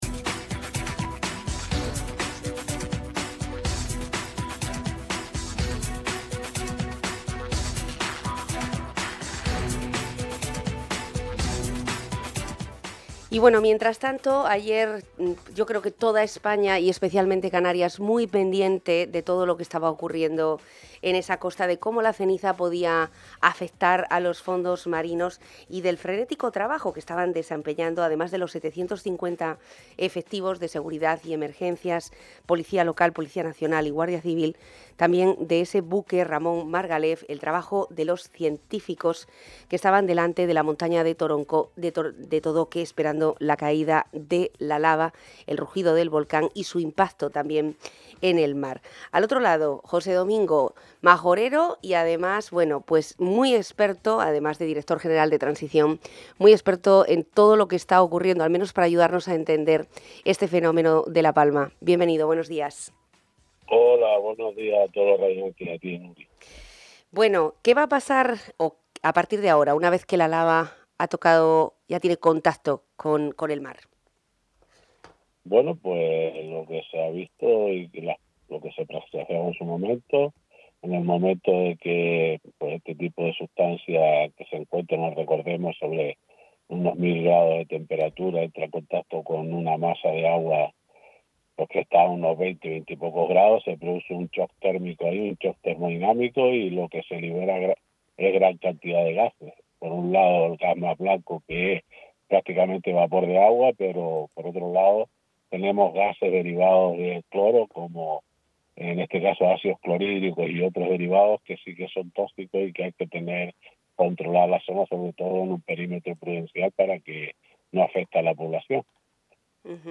José Domingo Fernández en El Magacín. El Director General de Transición Ecológica del Gobierno de Canarias ha explicado en el programa El Magacín la situación en La Palma, las hipótesis que se barajan después de haber entrado la lava en contacto con el mar y el trabajo que se desarrolla ahora mismo también institucionalmente hablando.